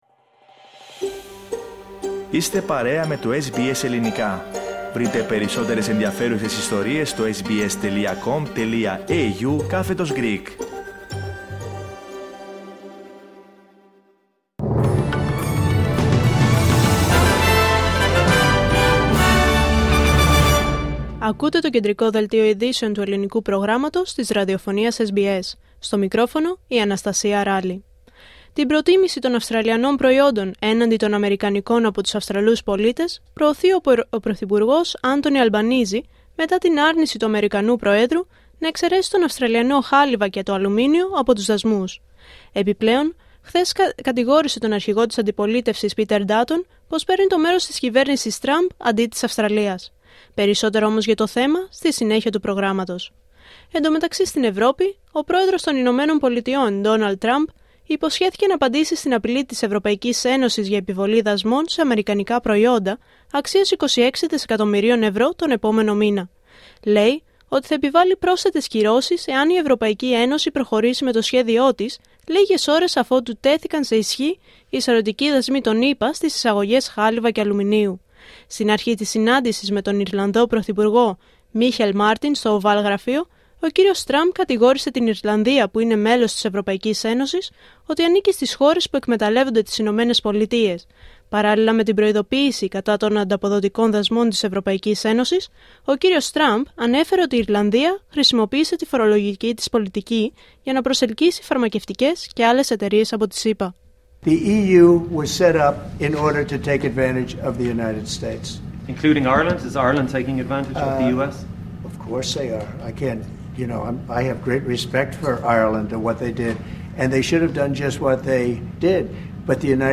Δελτίο Ειδήσεων Πέμπτη 13 Μαρτίου 2025